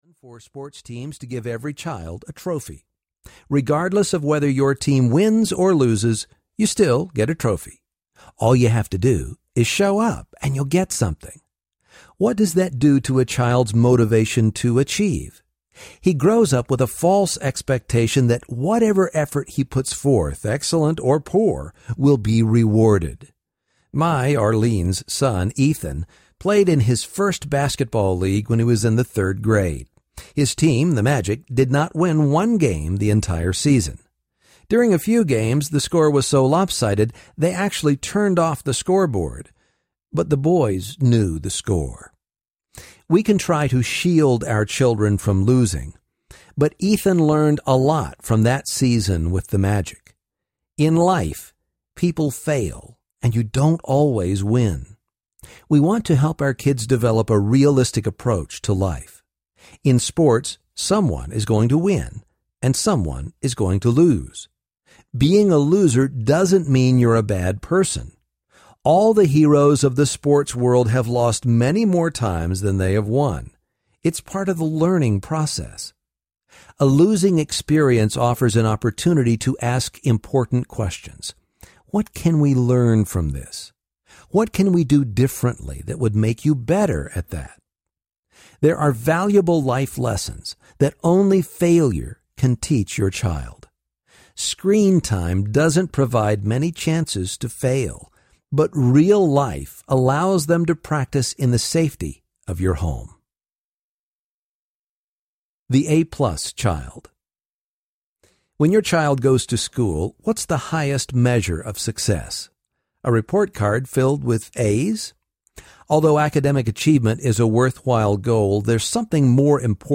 Growing Up Social Audiobook
Narrator
6.35 Hrs. – Unabridged